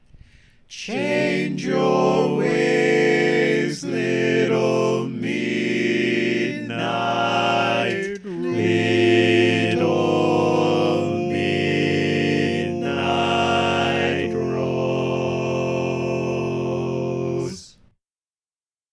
How many parts: 4
Type: Barbershop
All Parts mix:
Learning tracks sung by